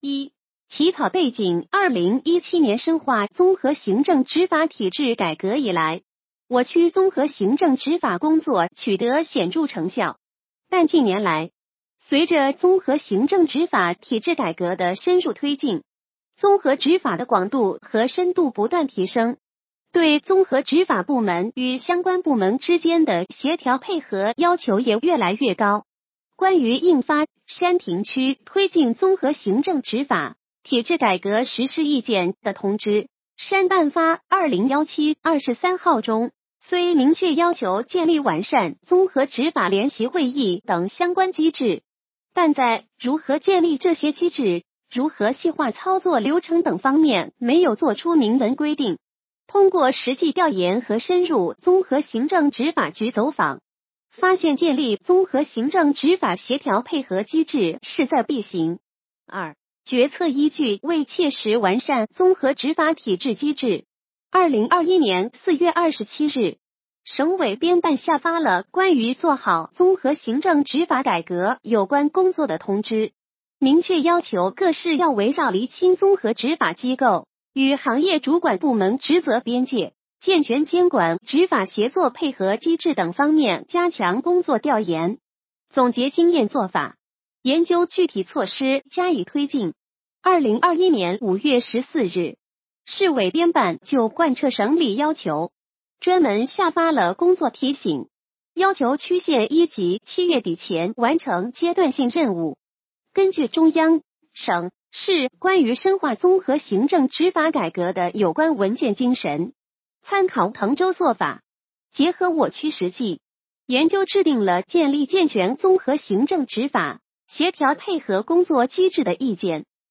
语音解读：山亭区人民政府办公室关于印发《建立健全综合行政执法协调配合工作机制的意见（试行）》的通知